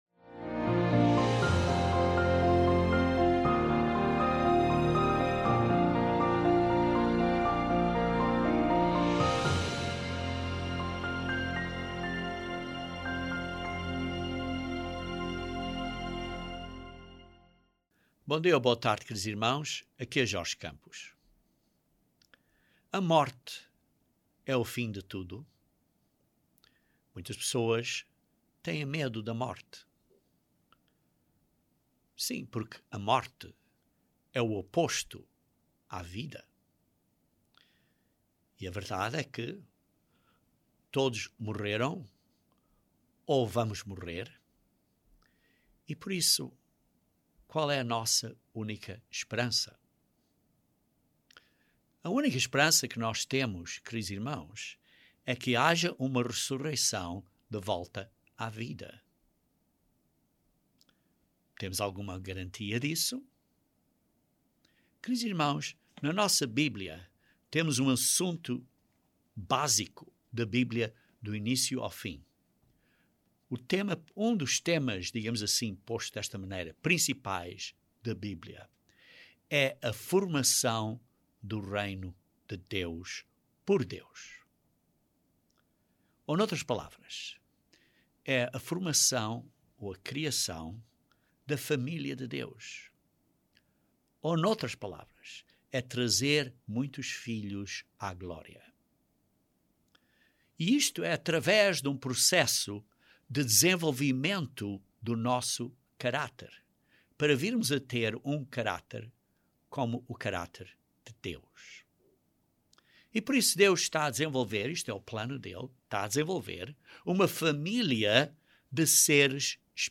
A morte é o fim de tudo, mas graças a Deus e à ressurreição de Jesus Cristo, temos esperança de viver de novo e Deus será justo para todos. Este sermão aborda este tema das ressurreições e do dia do juízo.